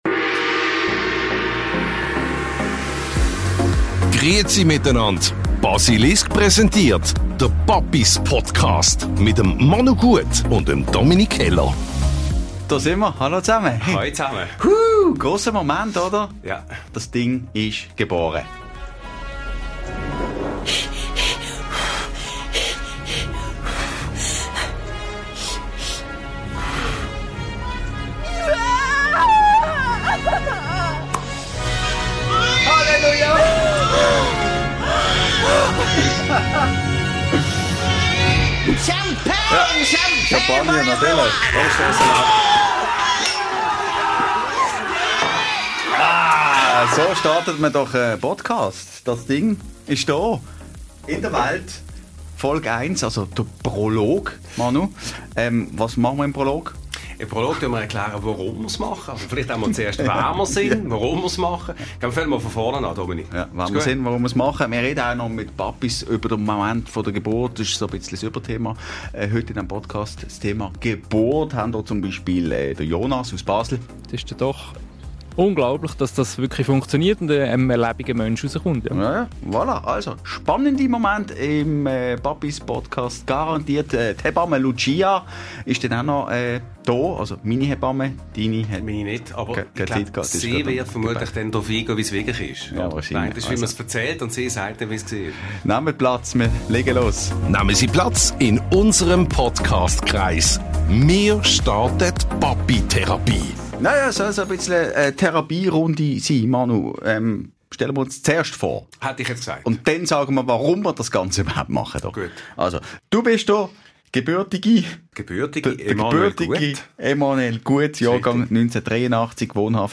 Beim Prolog dreht sich (fast) alles um das Thema «Geburt»: Der Podcast wird im Studio von Radio Basilisk gebührend mit viel Tamtam und Champagner in die Welt gesetzt.